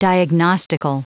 Prononciation du mot diagnostical en anglais (fichier audio)
Prononciation du mot : diagnostical